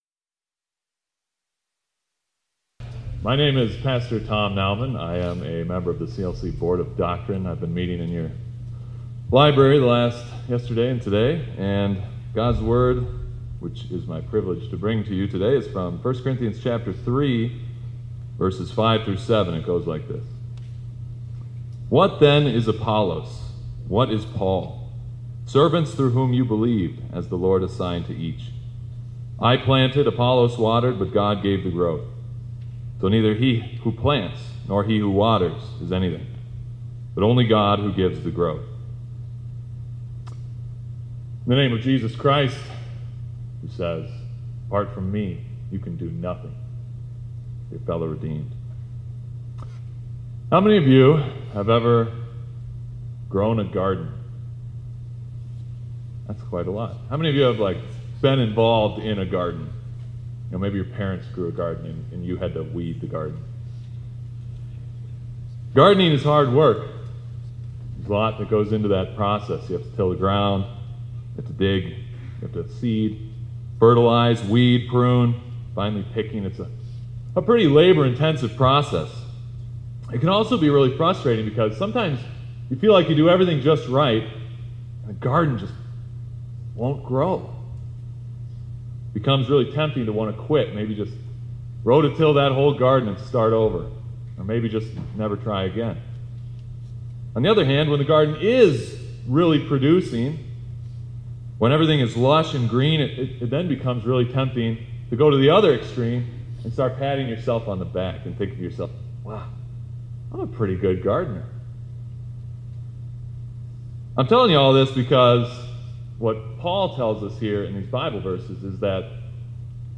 2026-04-14 ILC Chapel — Only God Makes the Garden Grow